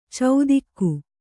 ♪ caudikku